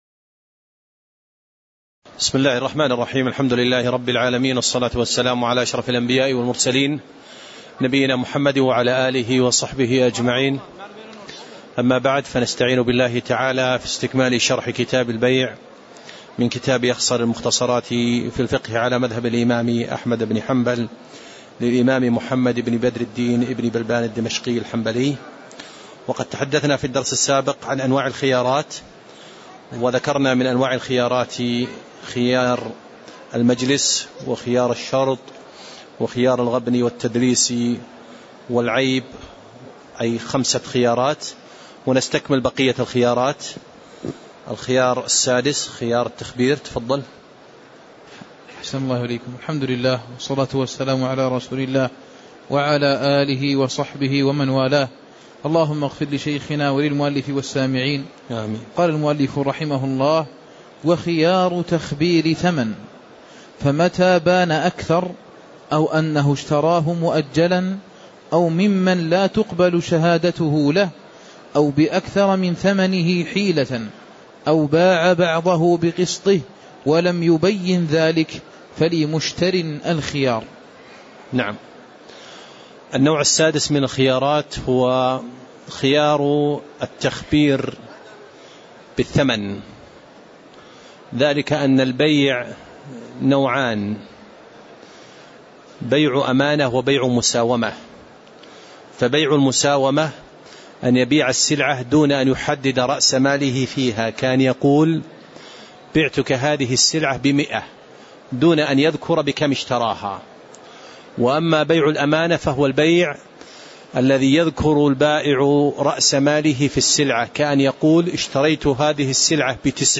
تاريخ النشر ٣٠ ربيع الأول ١٤٣٨ هـ المكان: المسجد النبوي الشيخ